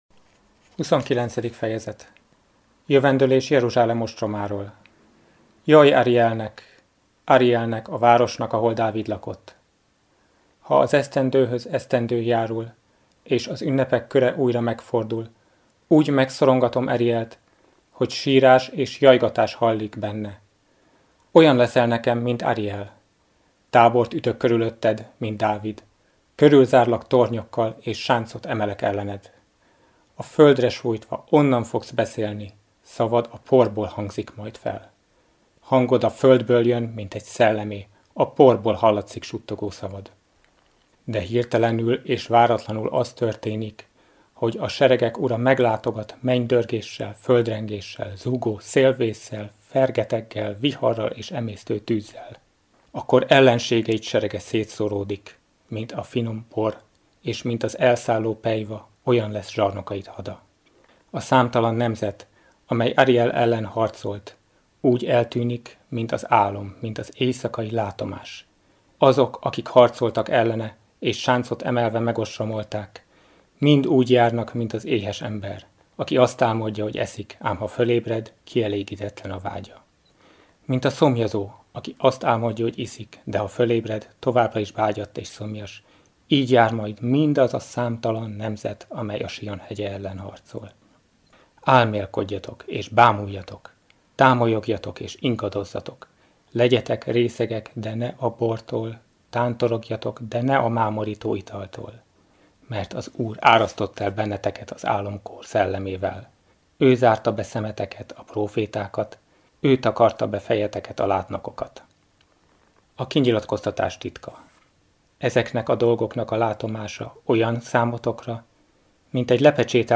Felolvasók: a Szeged-Tarjánvárosi Szent Gellért Plébánia hívei
A felvétel a Szent Gellért Plébánián készült 2024. augusztusában